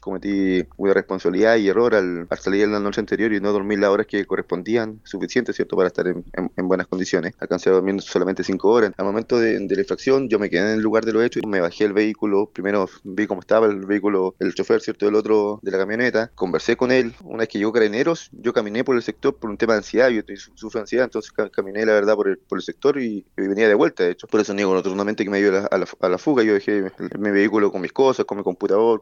Luego de sentarse en la silla de imputados, el consejero regional Mario Schmeisser se refirió a su episodio en conversación con La Radio, admitiendo que actuó con irresponsabilidad, indicando que compartió con amigos la noche anterior, bebió alcohol y no durmió las horas suficientes para conducir con normalidad.